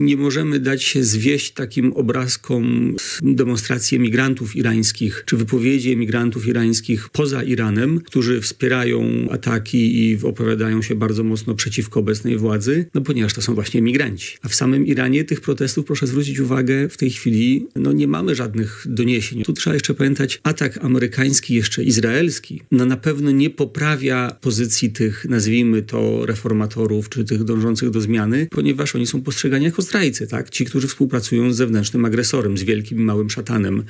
Ekspert o celach USA: Osłabienie militarne Iranu i zmiana władzy na przychylną